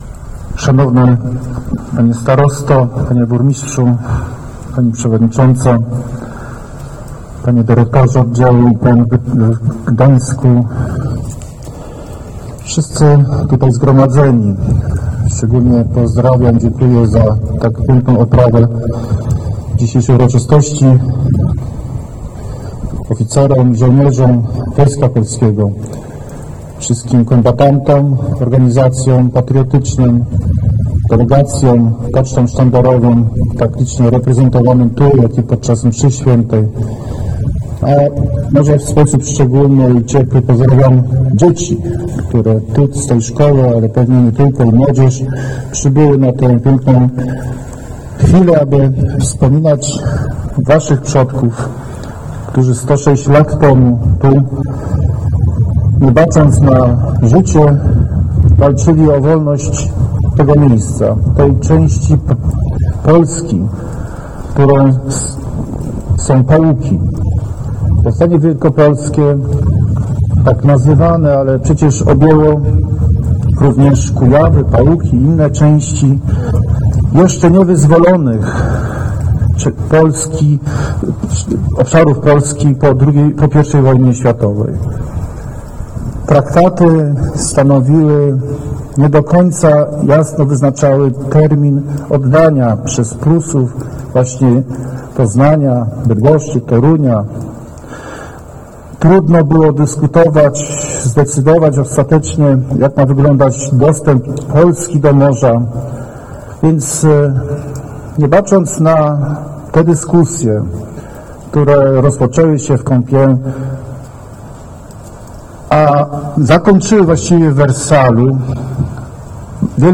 – mówił podczas ceremonii pod obeliskiem marszałek Piotr Całbecki.
Wystąpienie marszałka Piotra Całbeckiego (audio)